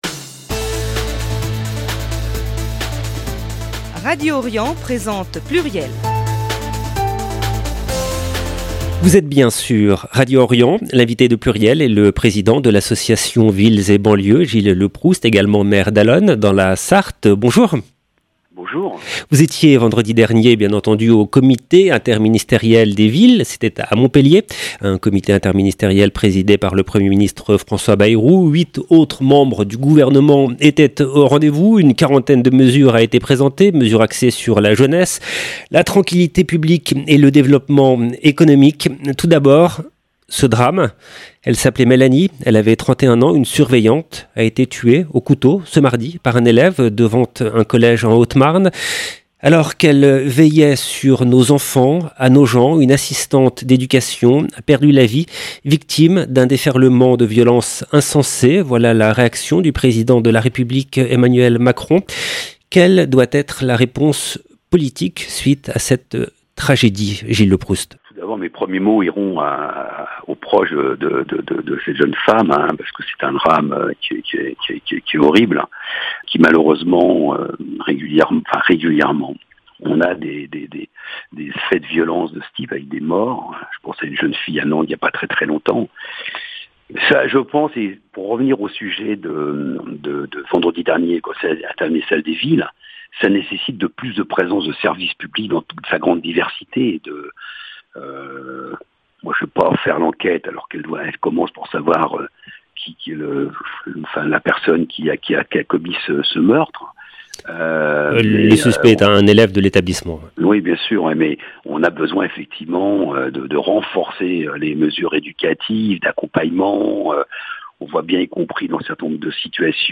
Pluriel reçoit le président de l’association Villes et Banlieues, Gilles Leproust également maire d’Allonnes dans la Sarthe